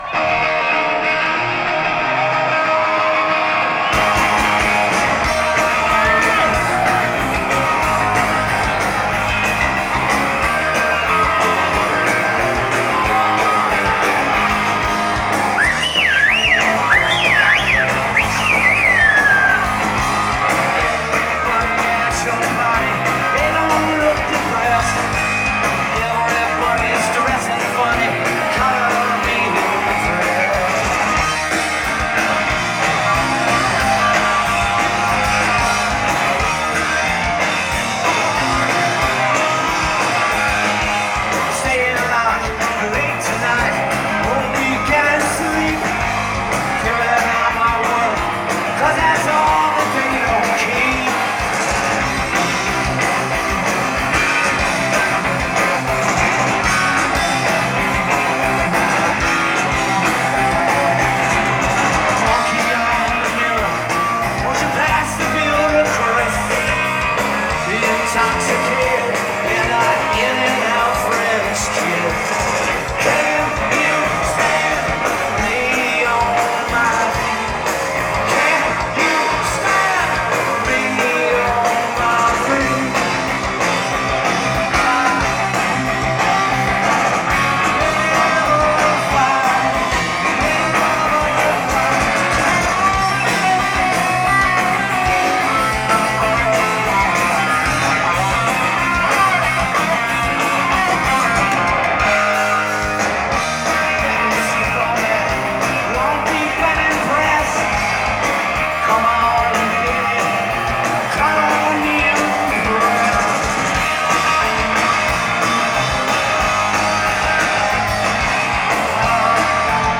Live at Riot Fest
At Fort York, Toronto, Ontario, Canada